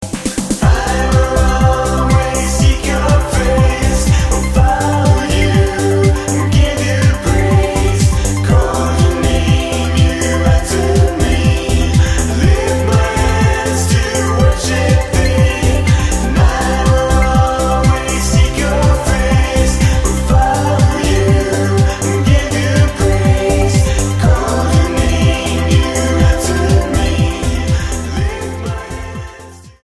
STYLE: Dance/Electronic
synth-pop trio